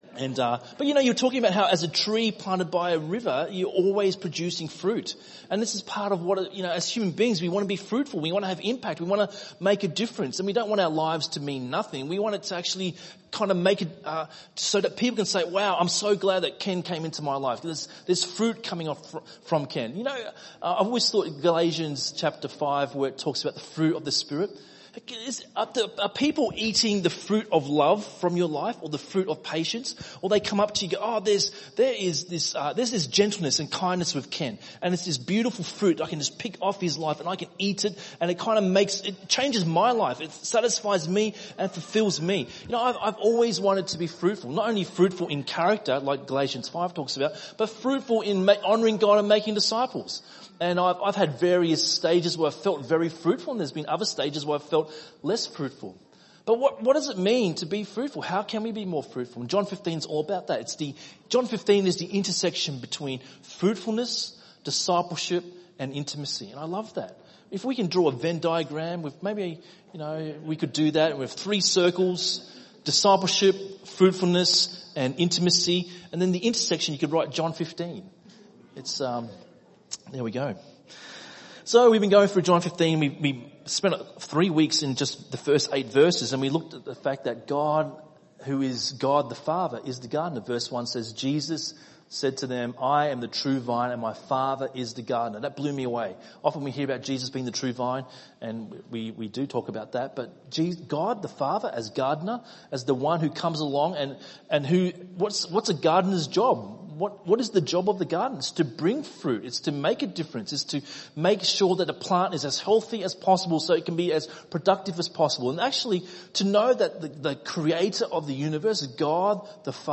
by enmelbourne | Nov 5, 2018 | ENM Sermon